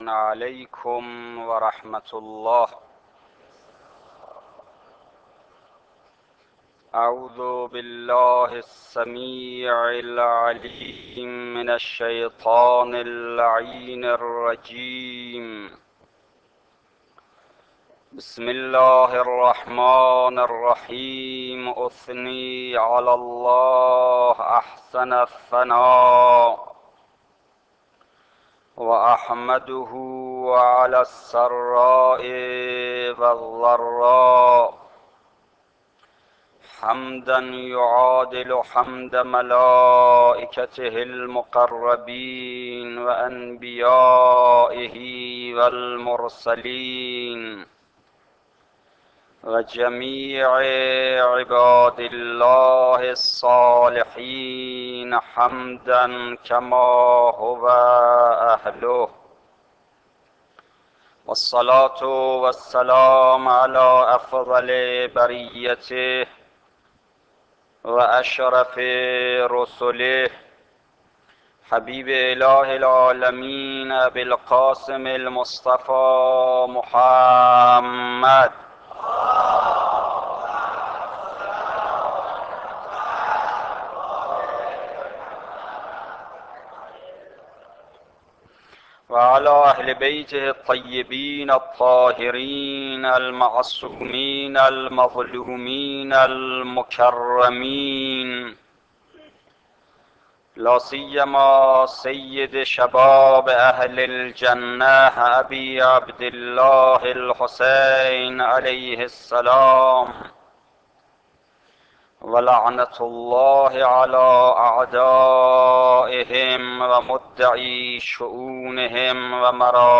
خطبه های ماه محرم